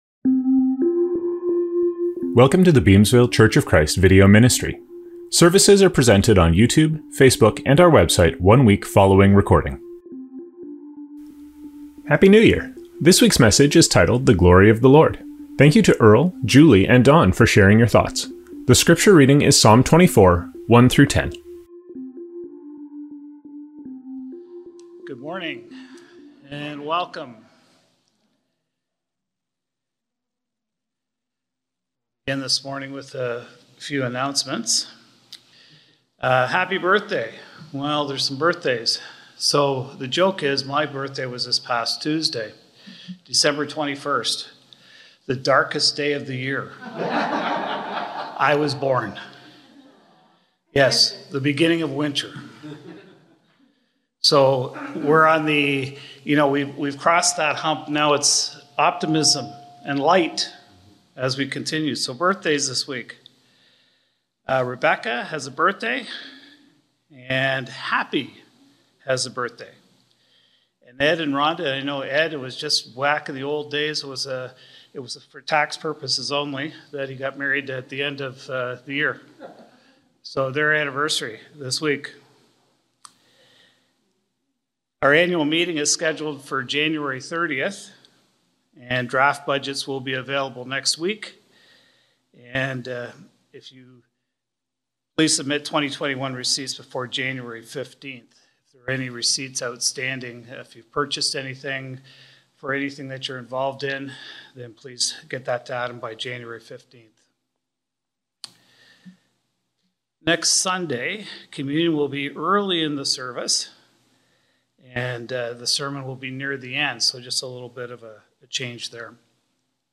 The scripture reading is Psalm 24:1-10.